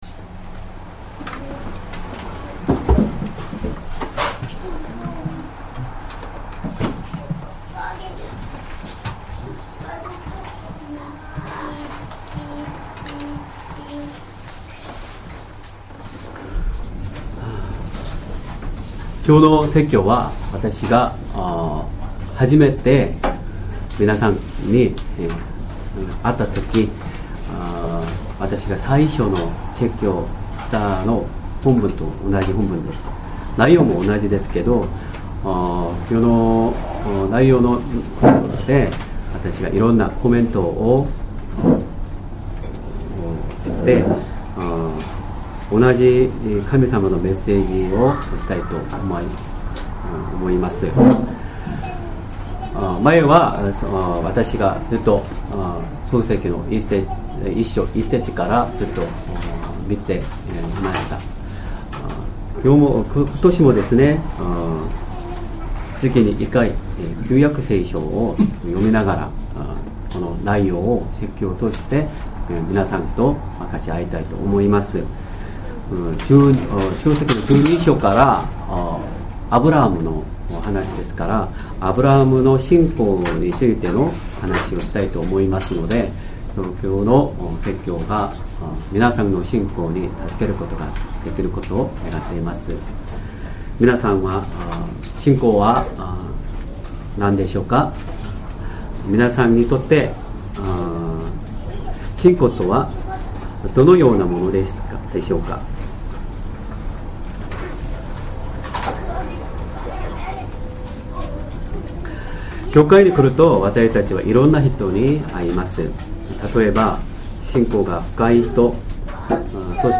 Sermon
2023年 1月21日 主日礼拝 説教 私たちを召して下さる主 創世記12章1～９節 :12:1 【主】はアブラムに言われた。